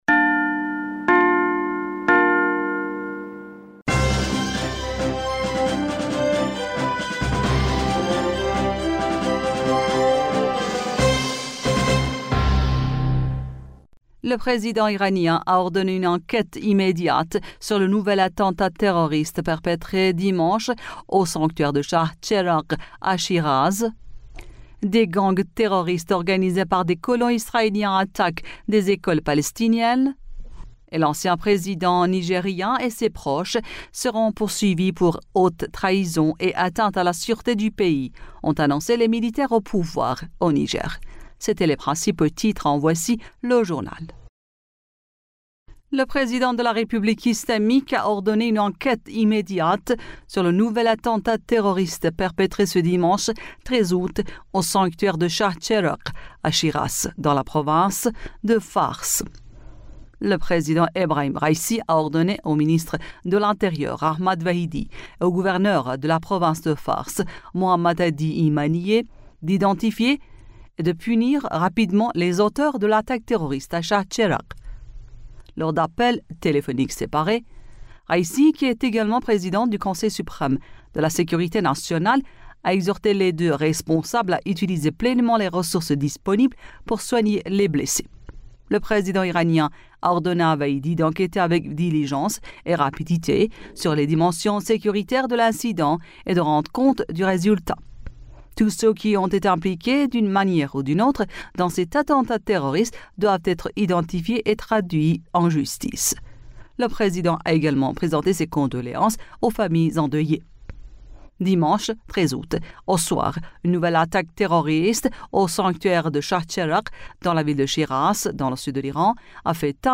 Bulletin d'information du 14 Aout 2023